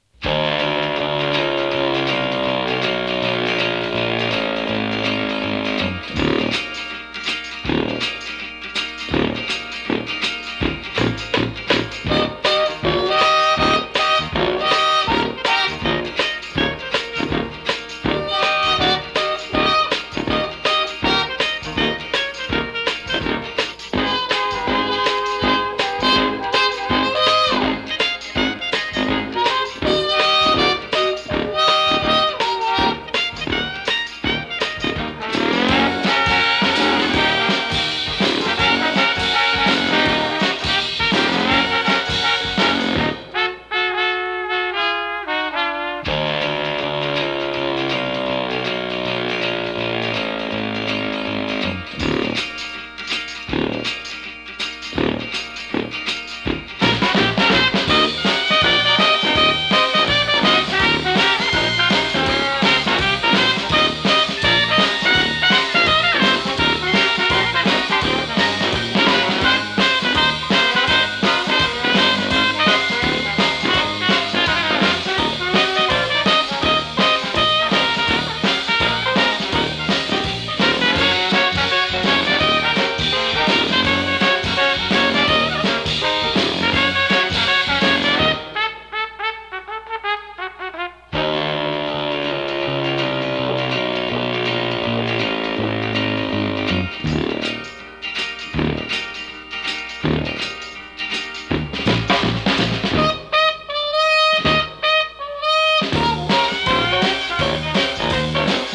60sポップスの数々をノスタルジックなスウィング・ジャズでカバー！